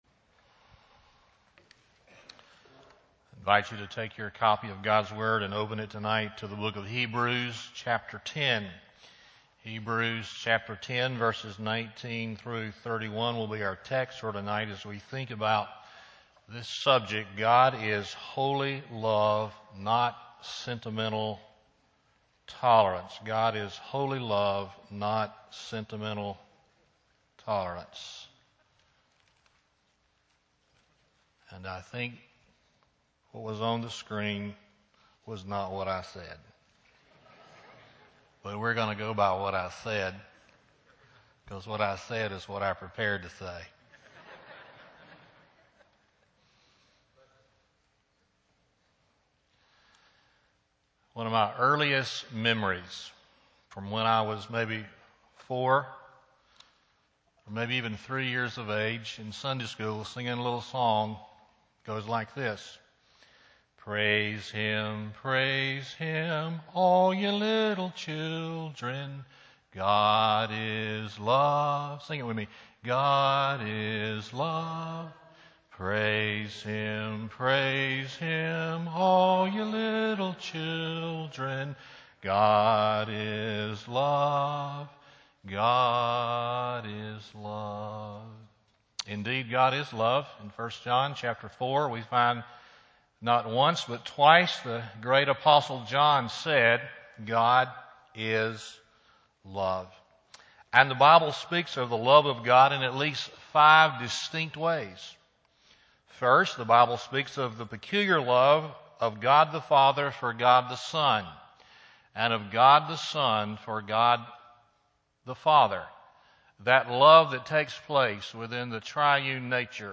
God Is Love, Not Sentimental Tolerance Sermon Date: August 28, 2011 PM Scripture: Hebrews 10 Sermon Series: God Is .